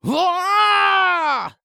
人声采集素材/男2刺客型/CK长声01.wav